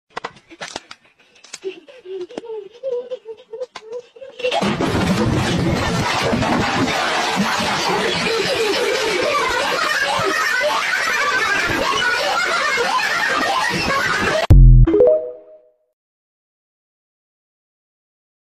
Memes Soundboard2 views